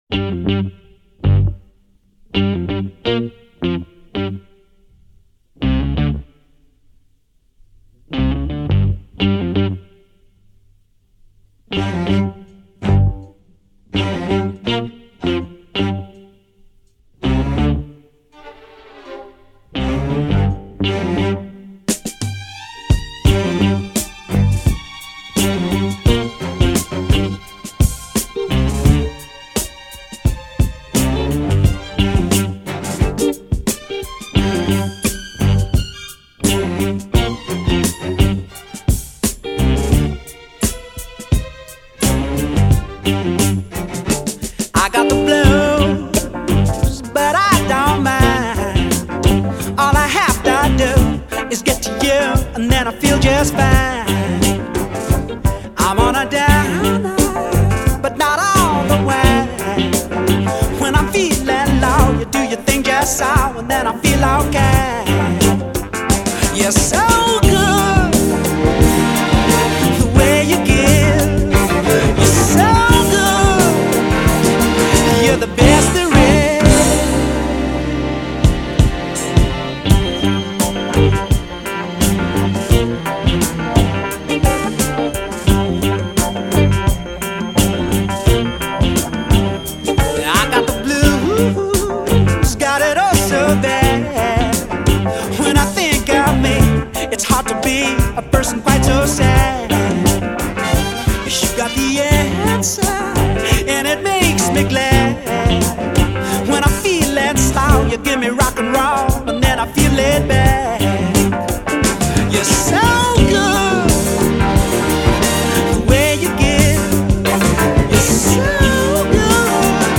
TEMPO :  89
• Premiere Partie (en Sol mineur)
• Voix puis choeur
• Fin : avec tempo doublé